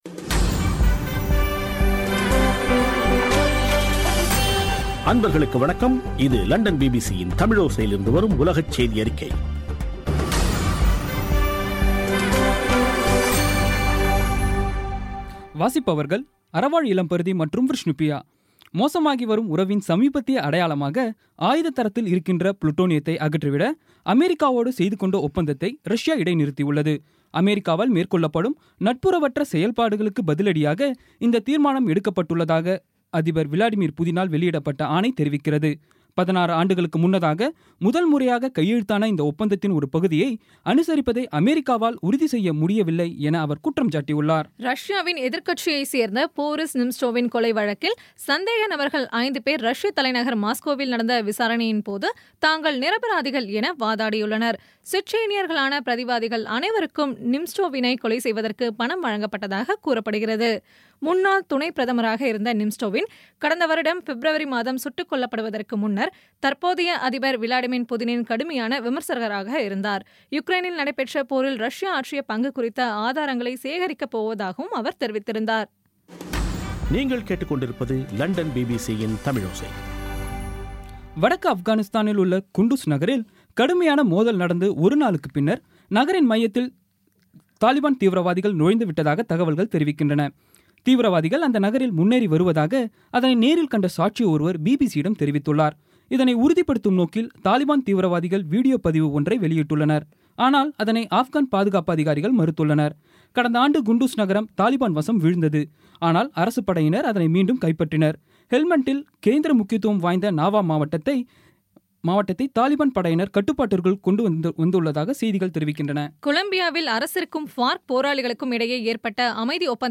இன்றைய (அக்டோபர் 3ம் தேதி ) பிபிசி தமிழோசை செய்தியறிக்கை